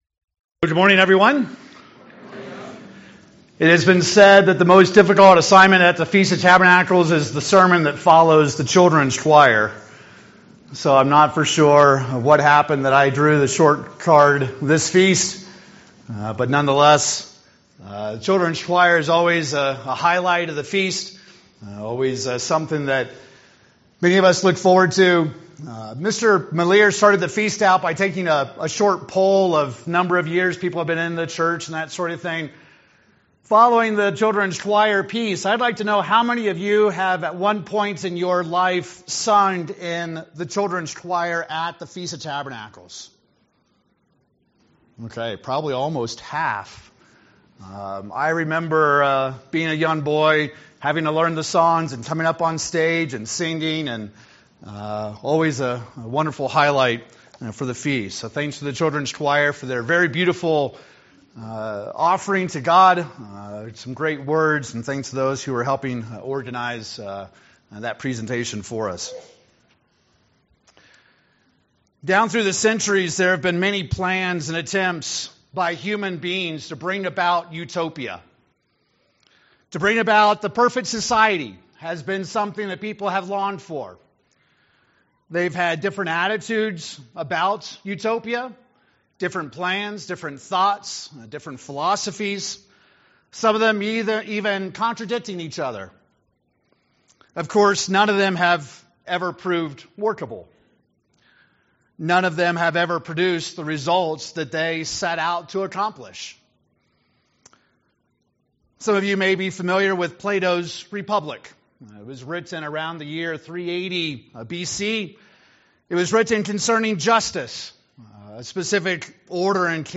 This sermon was given at the Steamboat Springs, Colorado 2018 Feast site.